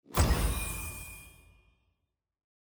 UI_Roundswitch01.mp3